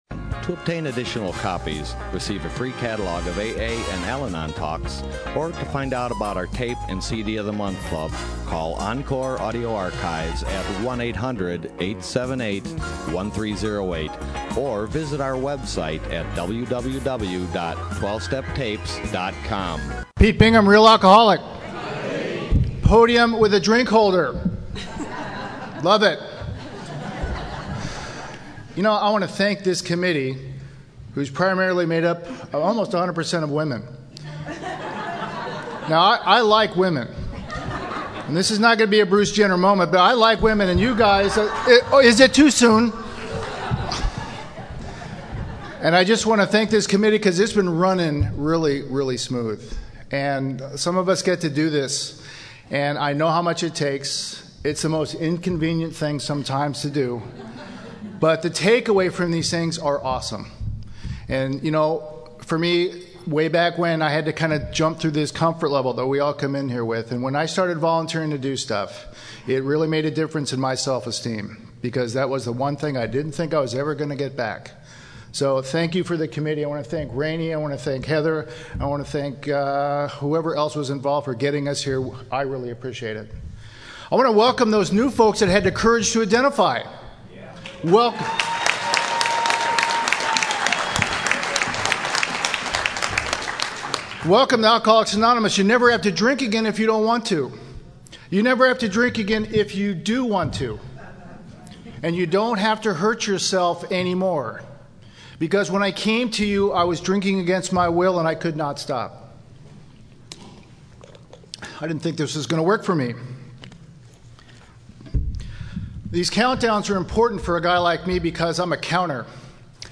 Serenity By The Sea 2015